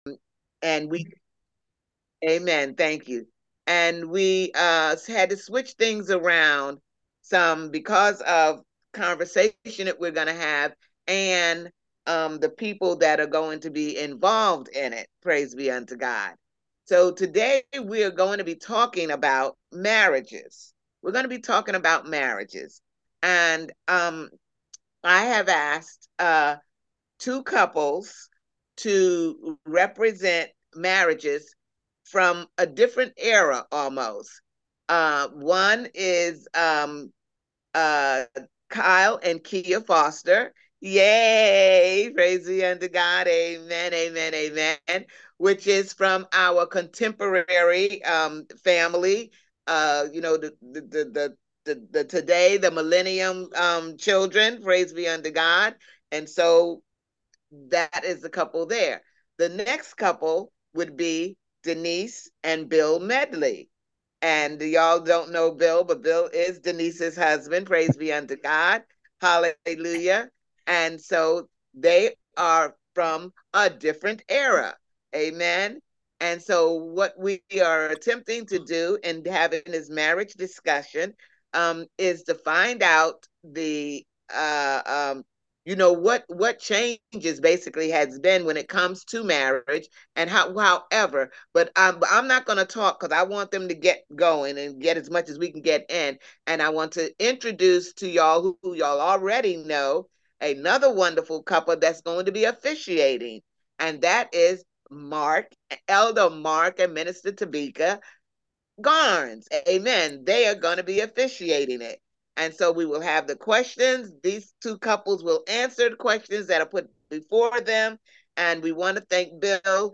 Service Type: Freedom Sunday